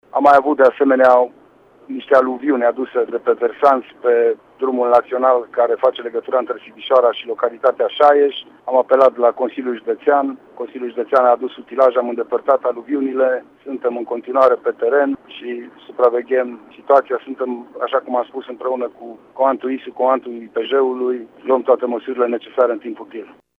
Revine Lucian Goga: